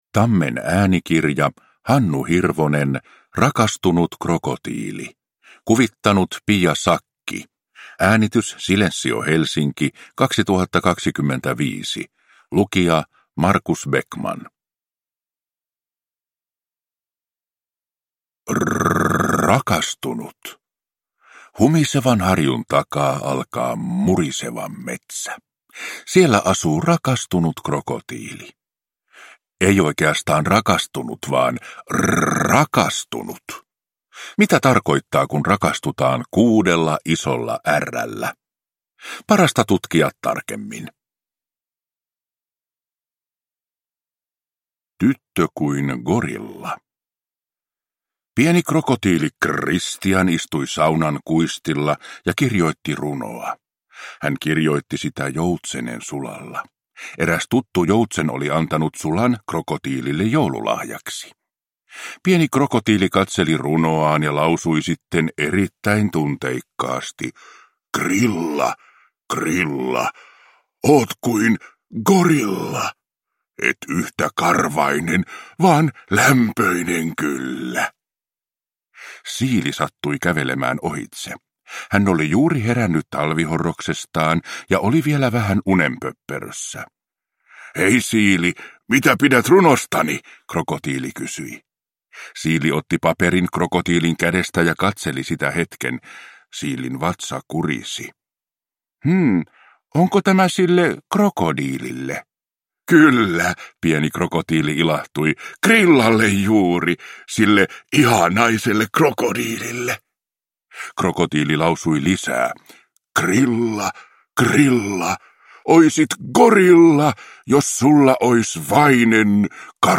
Rakastunut krokotiili – Ljudbok